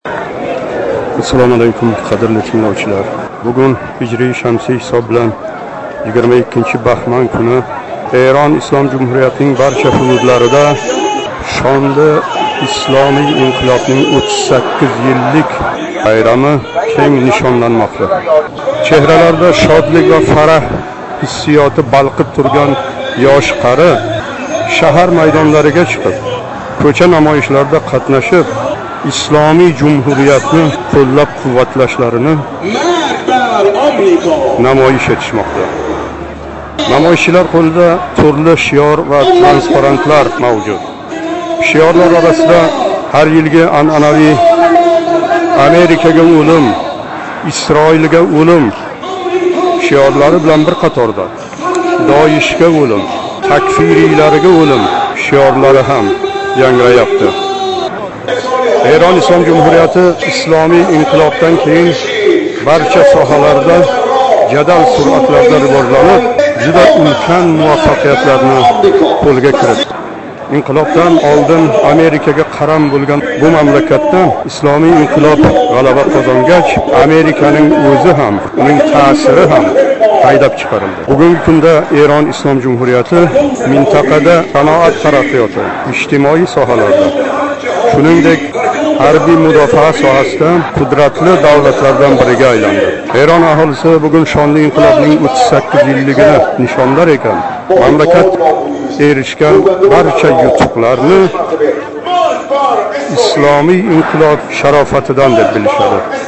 Муқаддас Машҳад шаҳрида 22-баҳман кунига бағишланган кўча намоишлари